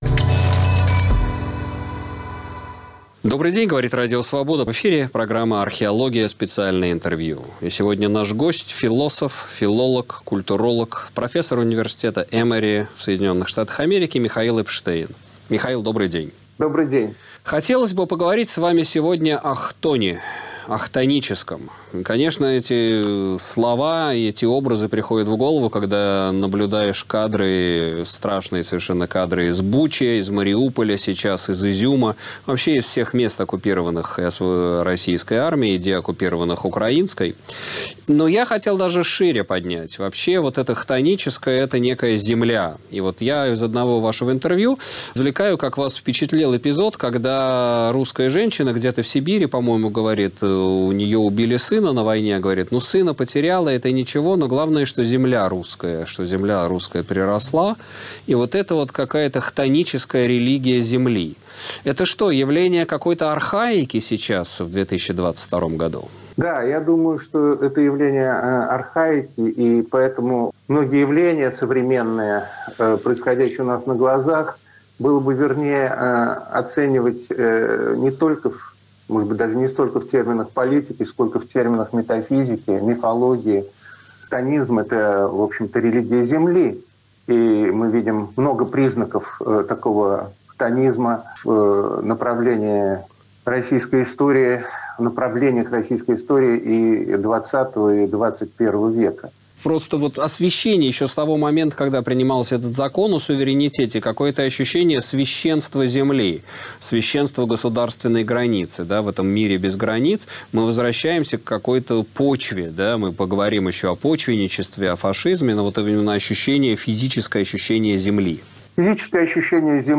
Нью-йоркский кулльтуролог и филолог отвечает на вопросы Сергея Медведева